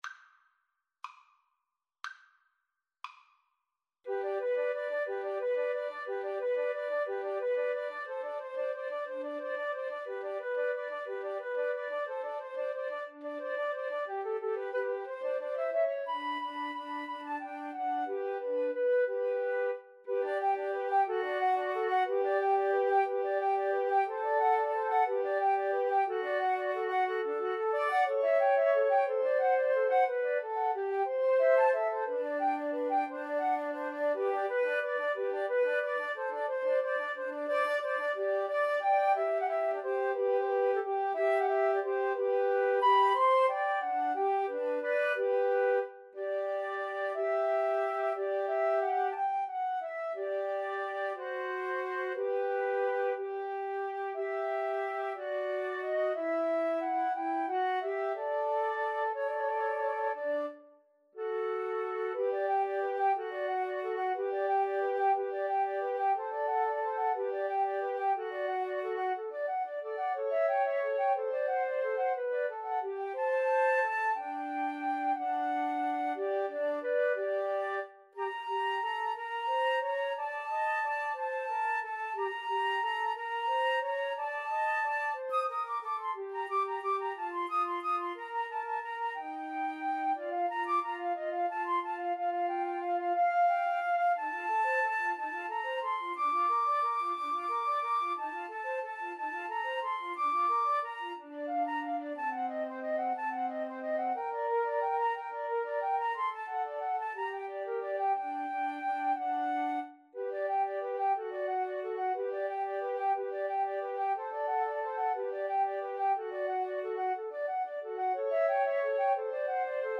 Flute 1Flute 2Flute 3
6/8 (View more 6/8 Music)
G major (Sounding Pitch) (View more G major Music for Flute Trio )
Molto lento .=c. 60
Classical (View more Classical Flute Trio Music)